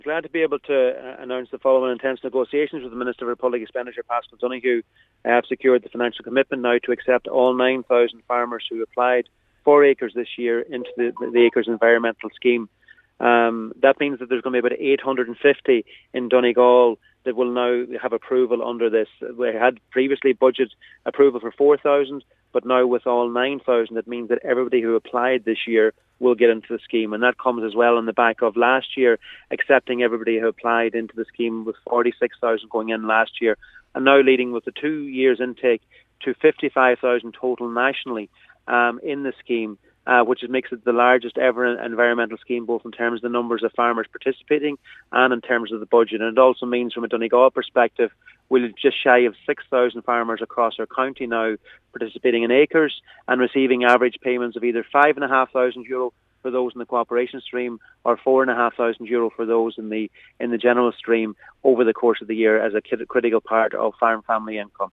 Minister for Agriculture, Charlie McConalogue says following negotiations with the Minister for Finance, he’s please to see the scheme grow: